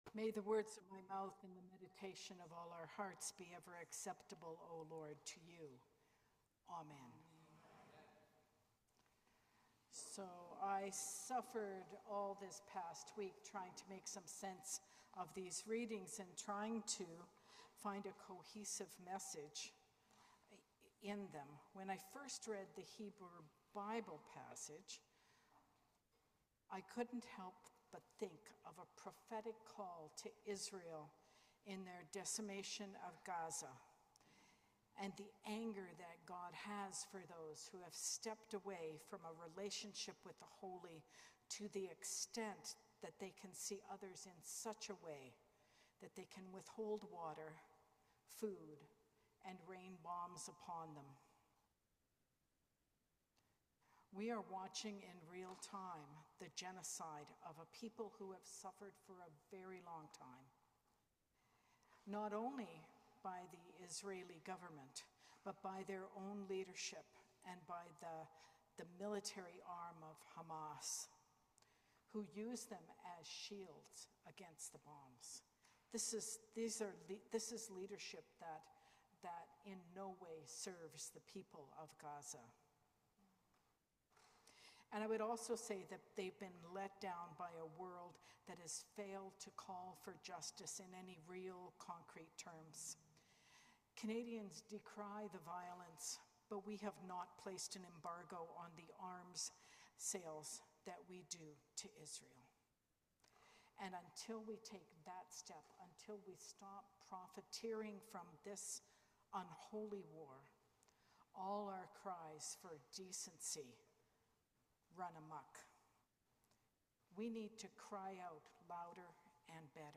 Sermon on the Twelfth Sunday after Pentecost